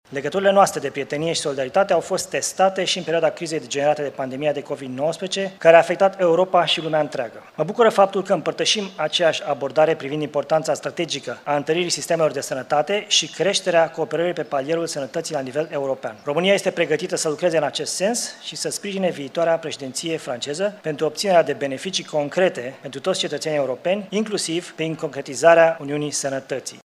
În prima jumătate a anului viitor, Franța va exercita Președinția rotativă a Consiliului Uniunii Europene, iar premierul Florin Cîțu a spus că țara noastră va sprijini cooperarea europeană în domeniul sănătății: